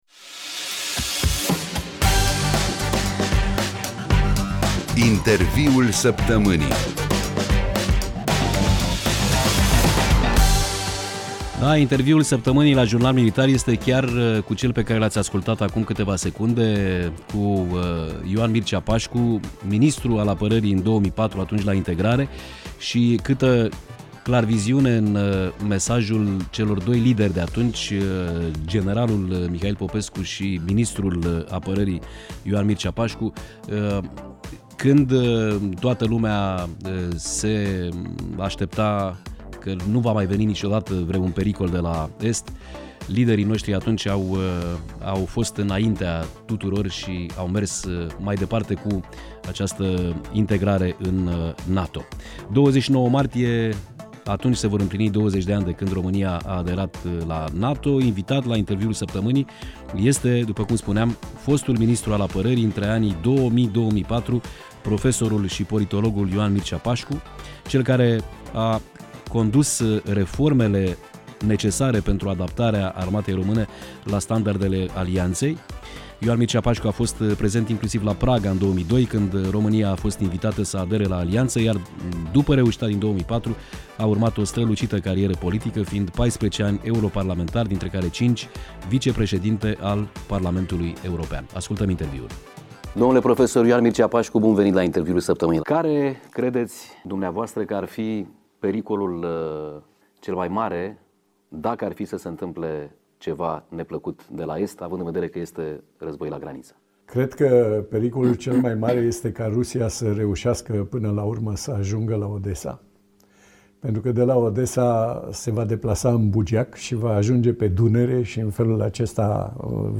Interviul-saptamanii-Ioan-Mircea-Pașcu.mp3